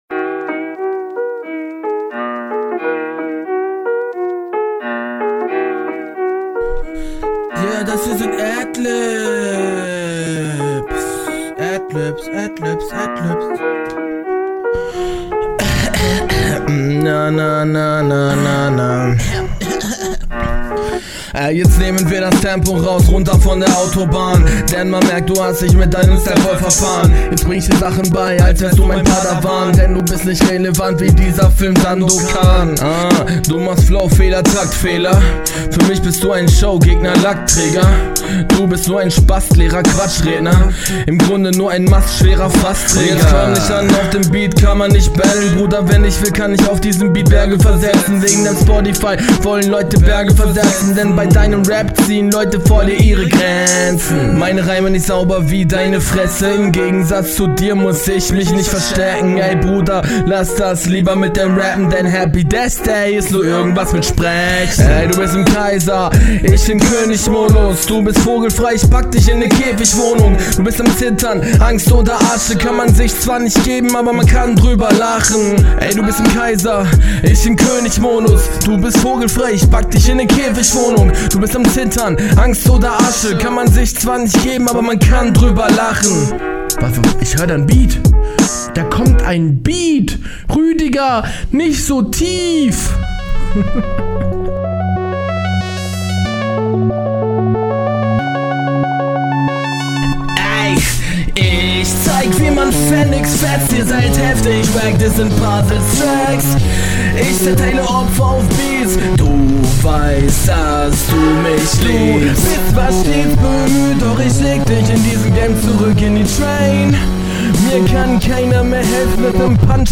Krasse Adlibs. Finde die Doubles hier bissl zu laut.